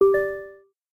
radio.ogg